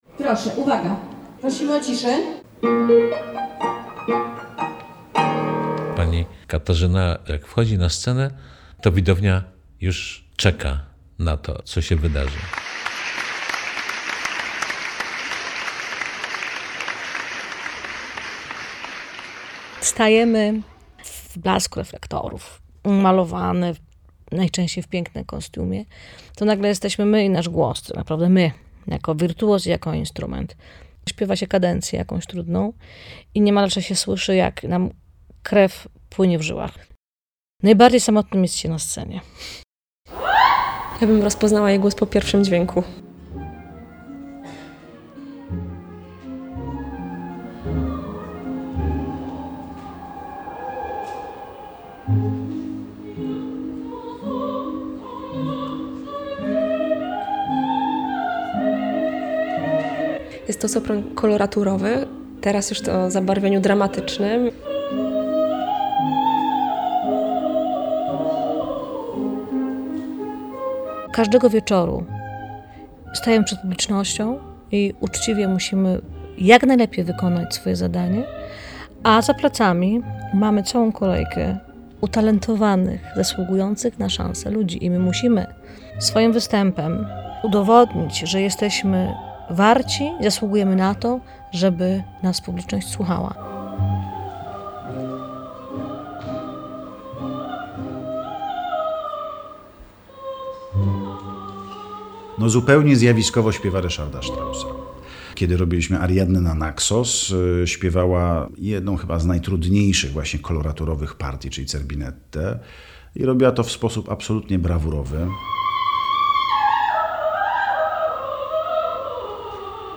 "Śpiewać własnym głosem" - reportaż